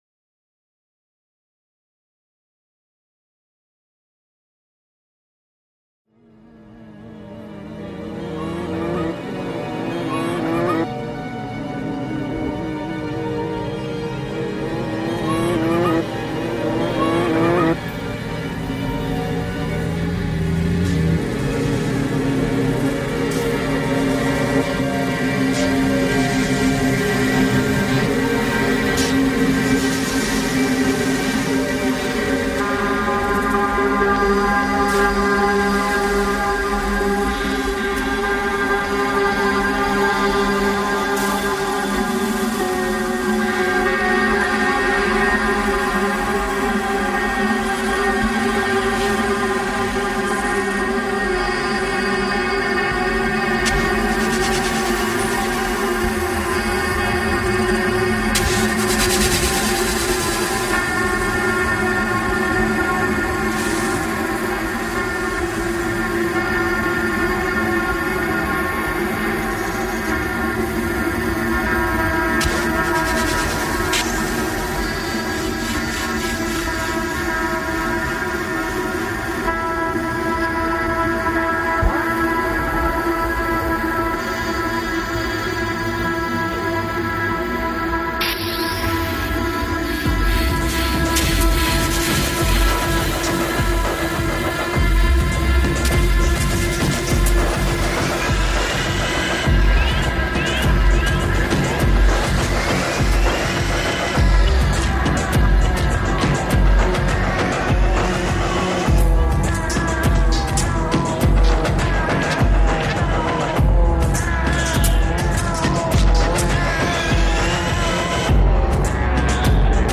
Pitt Rivers Museum, Oxford sound installation reimagined